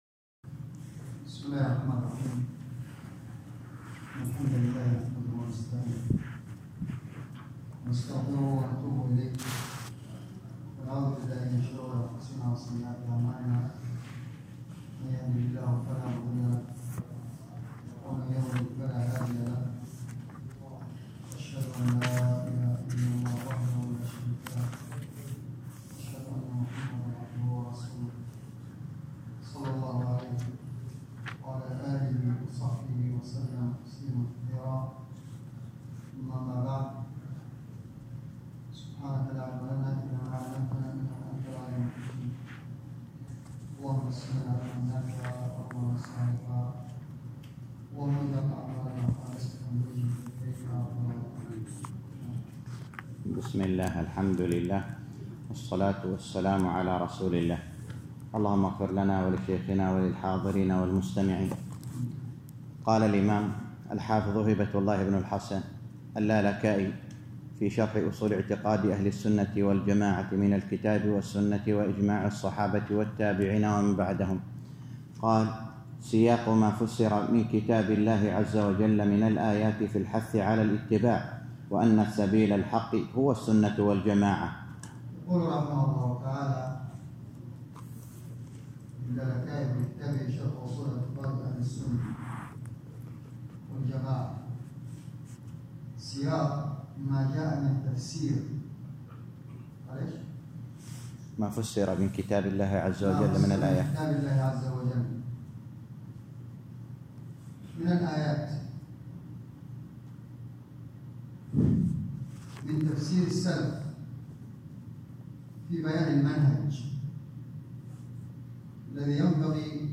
الدرس الخامس عشر - شرح أصول اعتقاد اهل السنة والجماعة الامام الحافظ اللالكائي _ 15